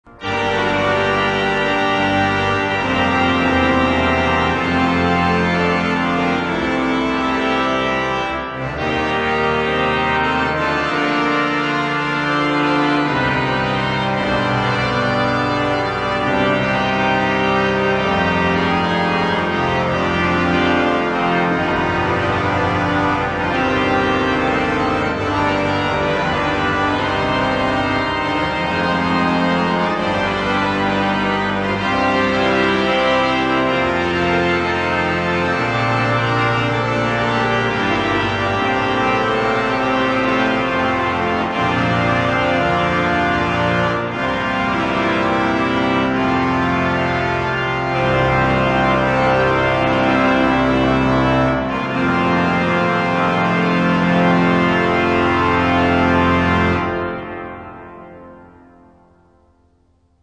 L'Orgue de St Rémy de Provence